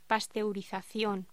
Locución: Pasteurización
voz